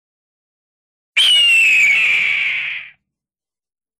Grito da aguia
grito-de-aguila-efecto.mp3